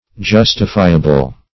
Justifiable \Jus"ti*fi`a*ble\, a. [Cf. F. justifiable.